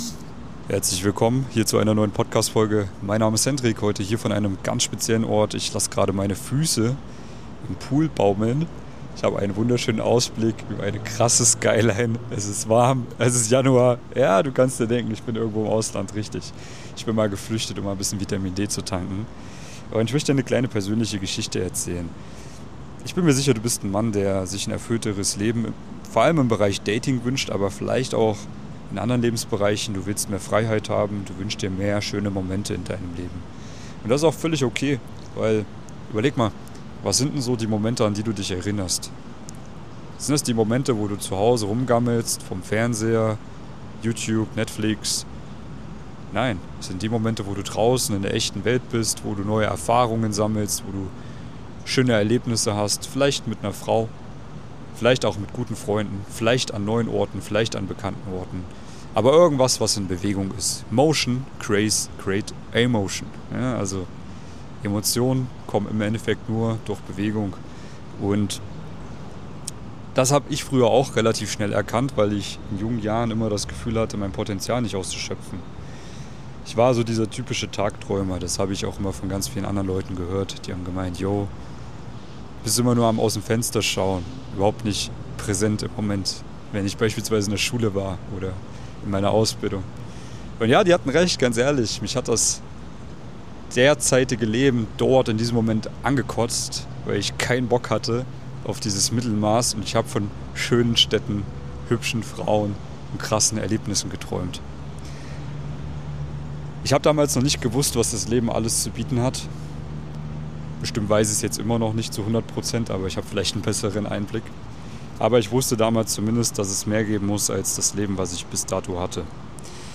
Ich sitze an einem Infinity-Pool hoch über den Dächern einer Millionenstadt, blicke über die Skyline und reflektiere meinen eigenen Weg.